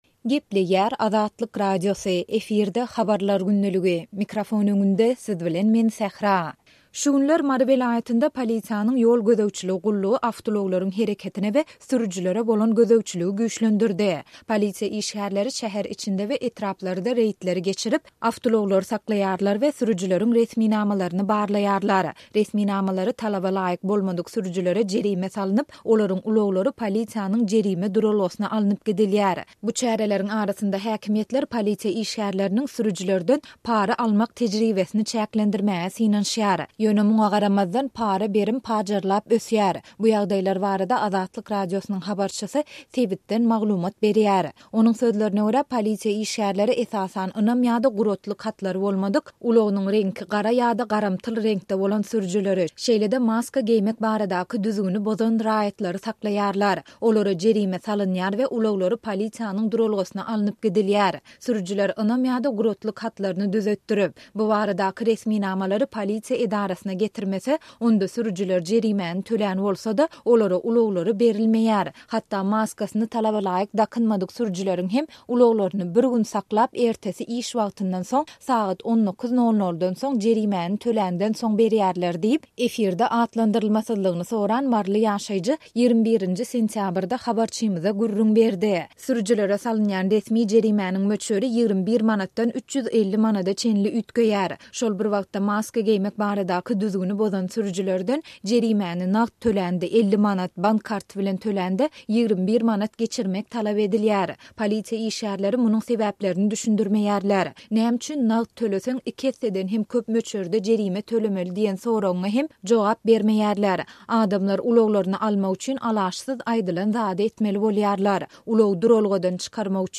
Ýöne muňa garamazdan, para-berim “pajarlap ösýär”. Bu ýagdaýlar barada Azatlyk Radiosynyň habarçysy sebitden maglumat berýär.